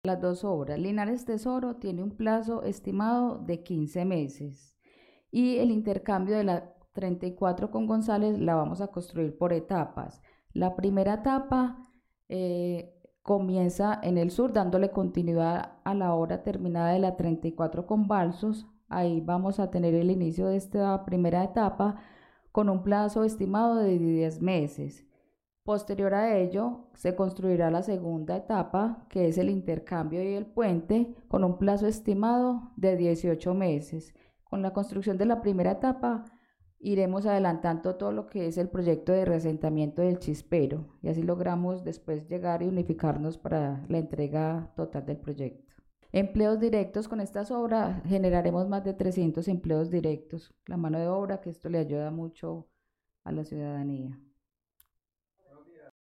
Palabras de Angélica Árias, directora Fonvalmed